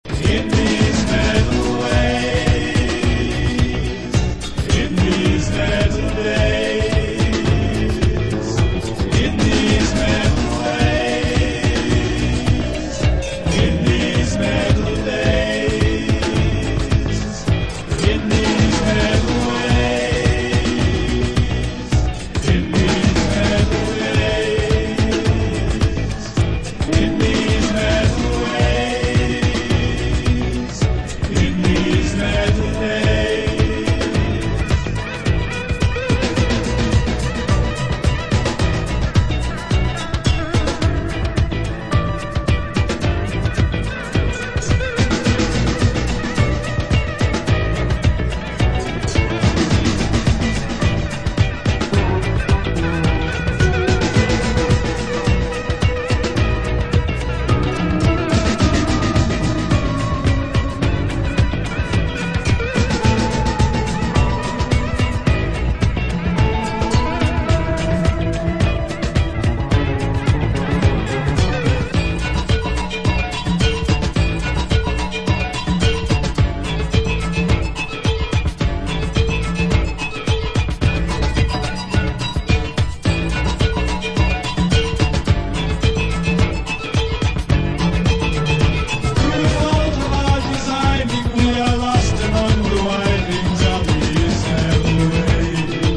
Obscure disco tracks